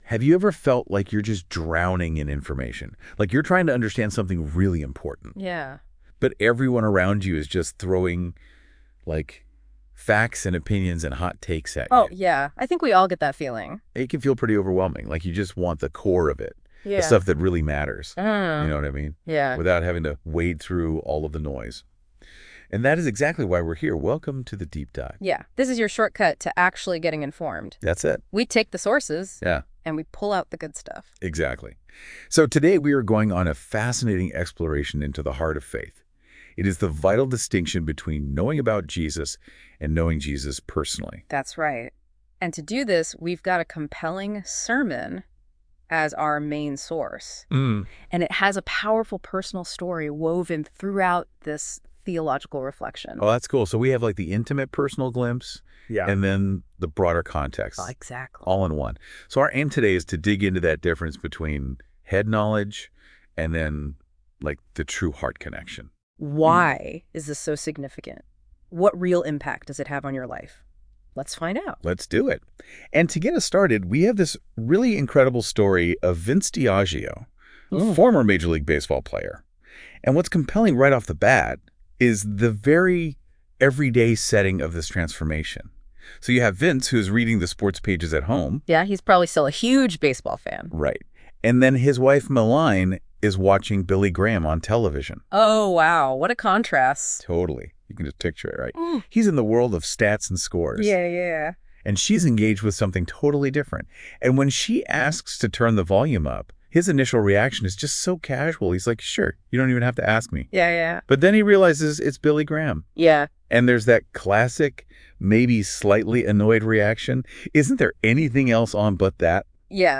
Ultimately, the sermon calls for a personal relationship with Jesus as Lord , not just intellectual assent to facts about Him. [NOTE: This "conversation" was created with the assistance of NotebookLM AI, and has been reviewed to ensure that an accurate presentation of the sermon is given.]